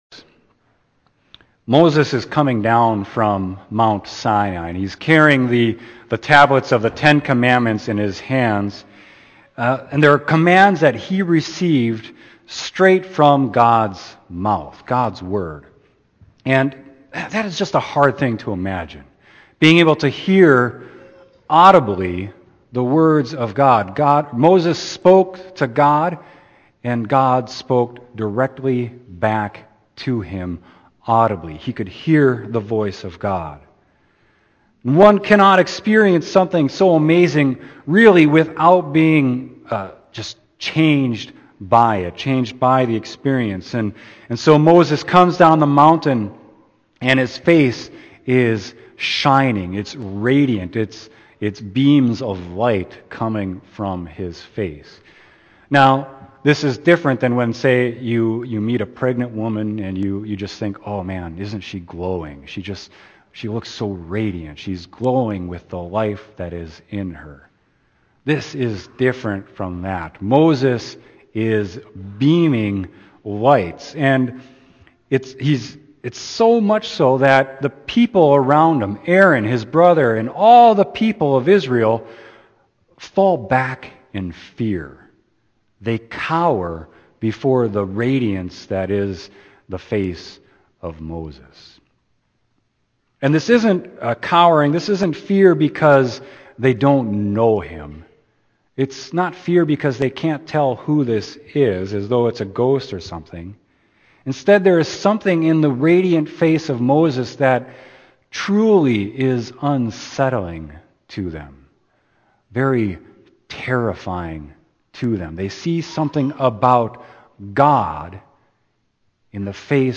Sermon: Exodus 34.29-35